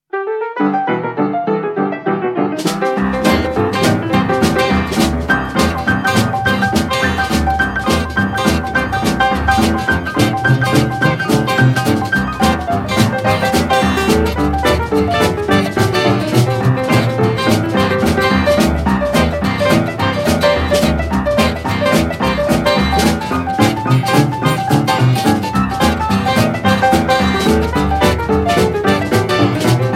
Boogie